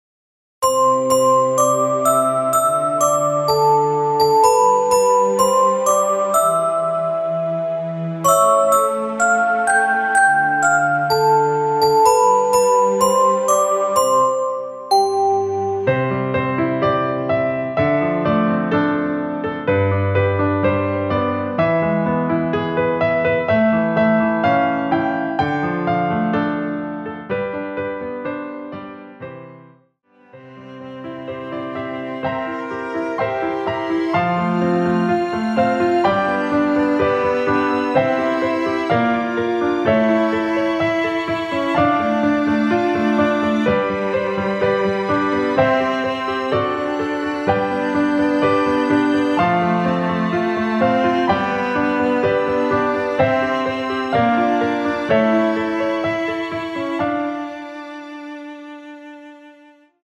대부분의 여성분이 부르실수 있는 키로 제작 하였습니다.
라라라 부분을 한번만 하고 끝나게 편곡 하였습니다.
앞부분30초, 뒷부분30초씩 편집해서 올려 드리고 있습니다.